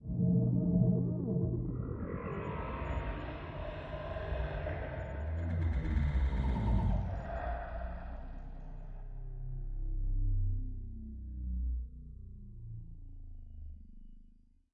描述：由各种合成器制成的变压器的声音